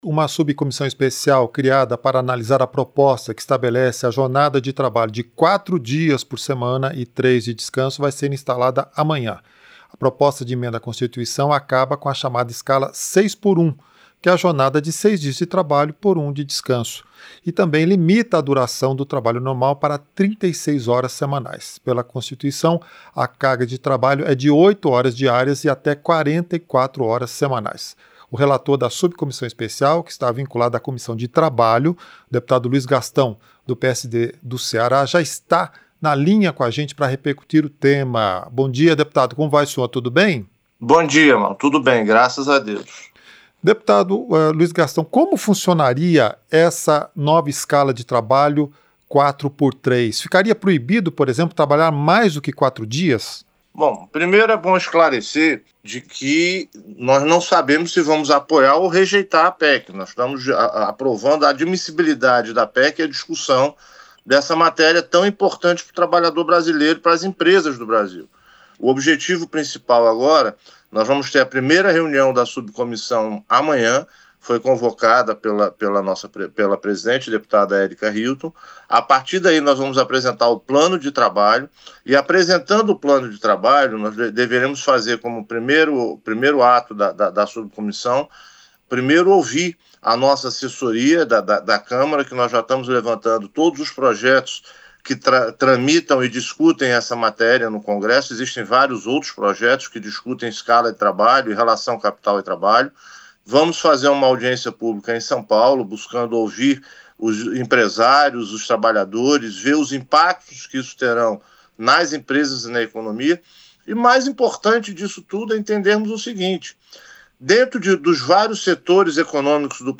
• Entrevista - Dep. Dep. Luiz Gastão (PSD-CE)
Programa ao vivo com reportagens, entrevistas sobre temas relacionados à Câmara dos Deputados, e o que vai ser destaque durante a semana.